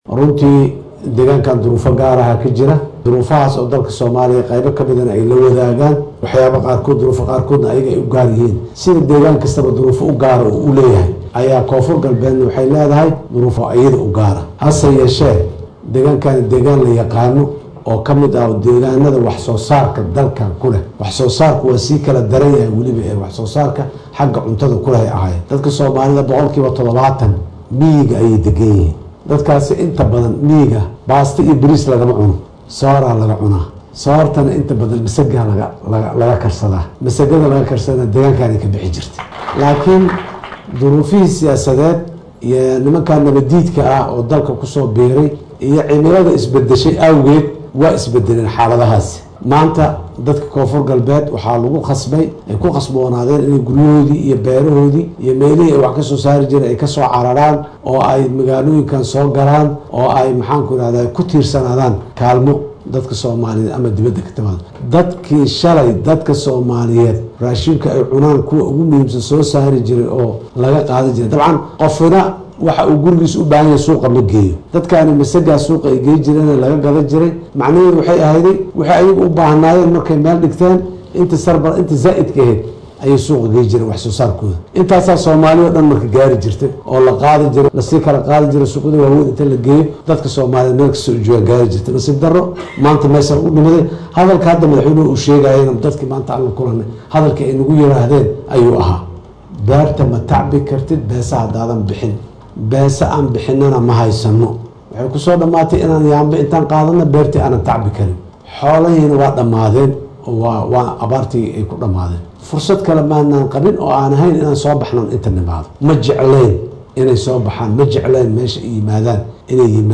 Madaxweynaha ayaa hadalkan ka sheegay munaasaabad xalay ka dhacday magaalada Baydhabo, taasoo uu kusoo gabagabeynayay socdaalkiisii uu ku joogay maamulka Koonfur Galbeed.